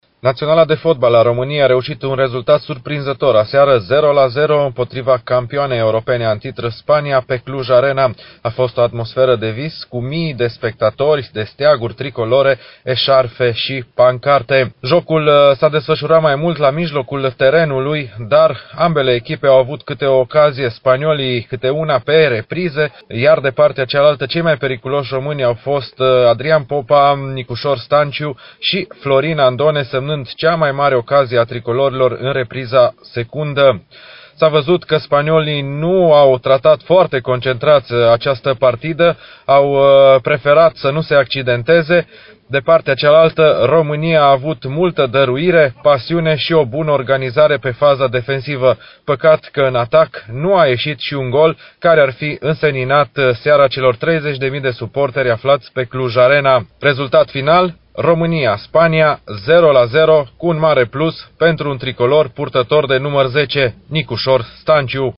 cronica-romania-spania.mp3